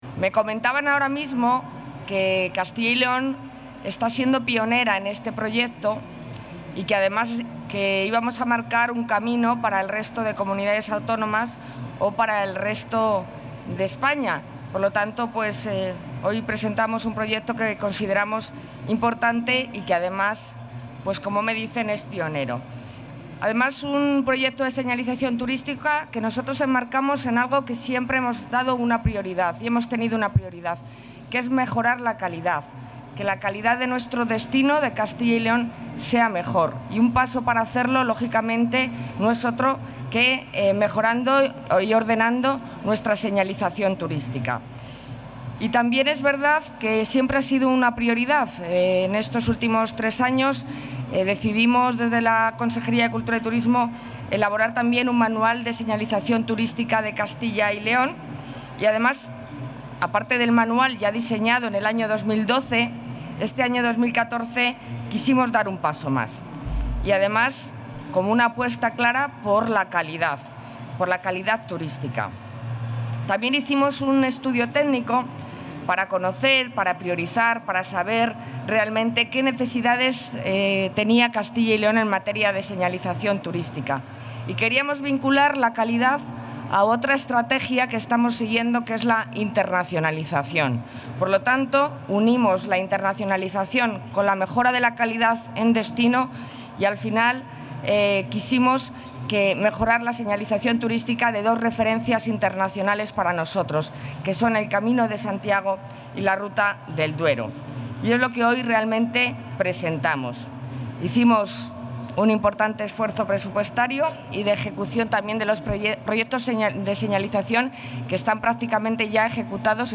La consejera de Cultura y Turismo, Alicia García, ha presentado en el marco de la feria Intur, el Programa de señalización...
Audio Consejera.